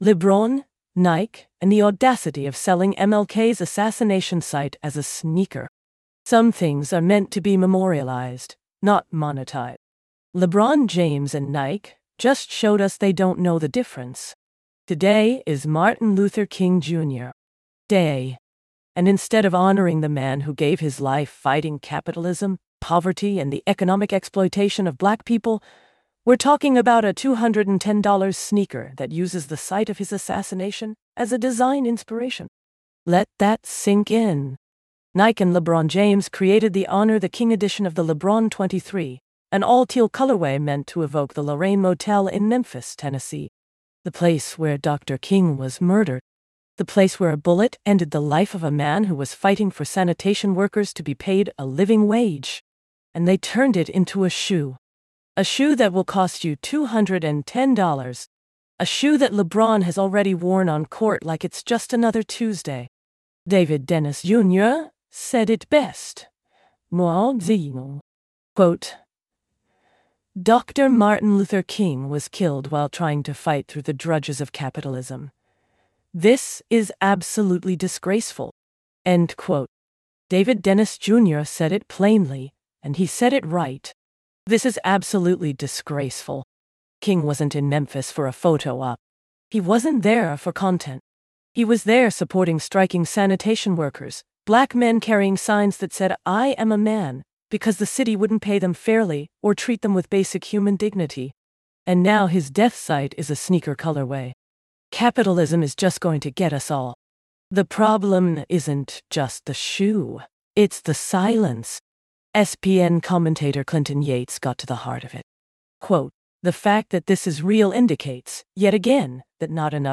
AI Editorial Assistant • ~8 minutes